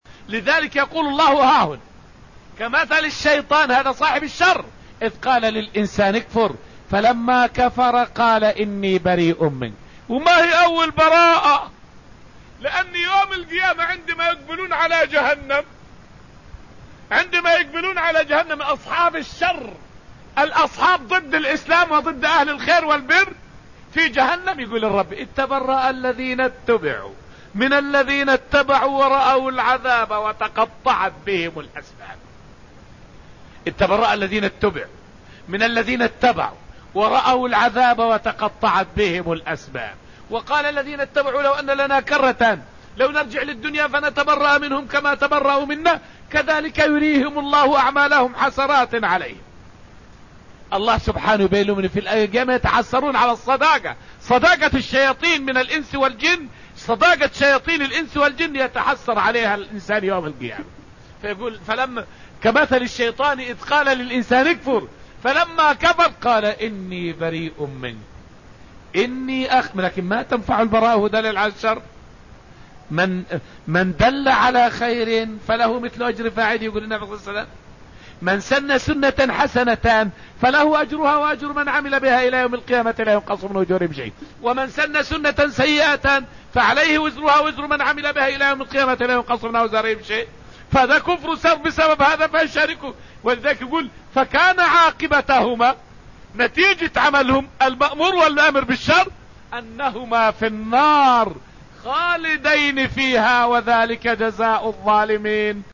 فائدة من الدرس التاسع من دروس تفسير سورة الحشر والتي ألقيت في المسجد النبوي الشريف حول براءة المتبوعين من أتباعهم يوم القيامة.